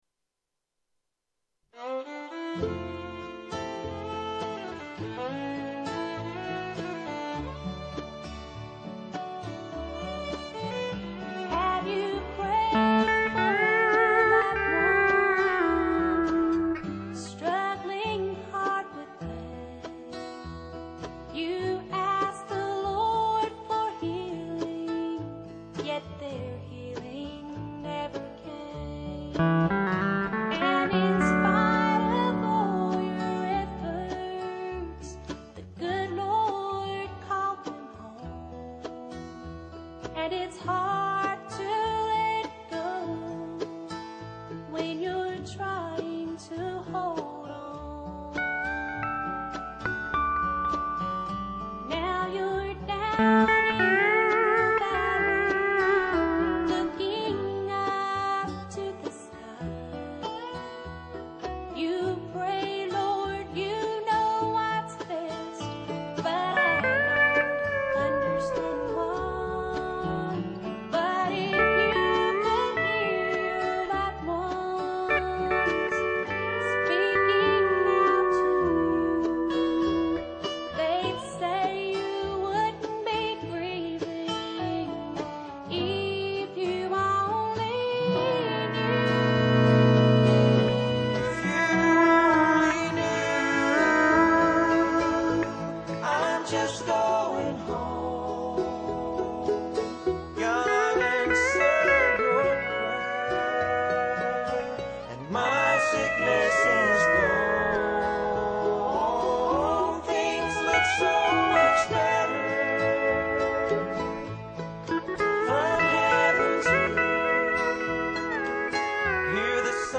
I recently remixed all the songs in stereo.
louder steel